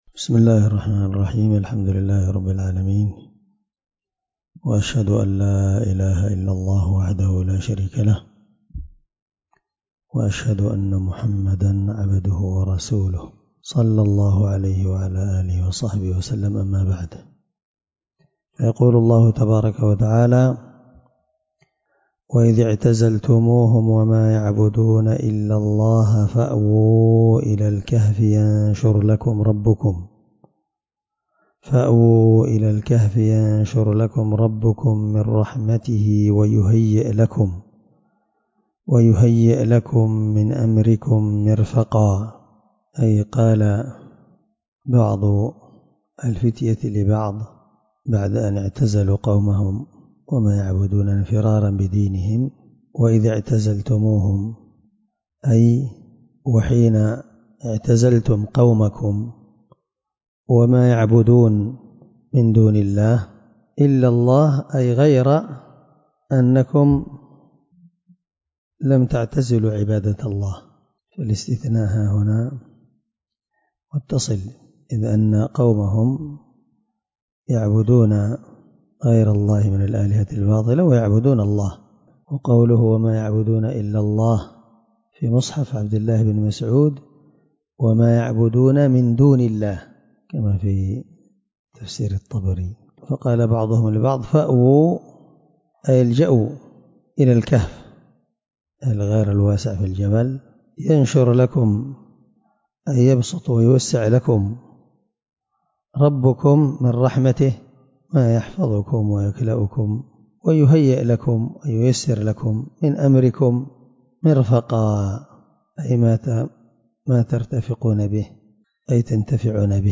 مع قراءة لتفسير السعدي